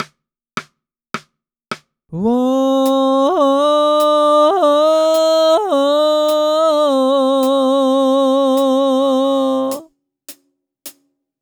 マイクスタンドやポップガードを使わずハンドマイクで収音。
ノンエフェクトでノイズ処理なども一切しておりません。
少し“硬い”と評される事もあるMOTUの音ですが、M2を入手しその出音を何となく理解できた気がします。
UltraLite直で録音。
Pre-Test-Ultralight-Mk3.wav